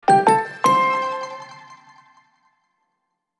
joenja-reminder-tone-spanish.mp3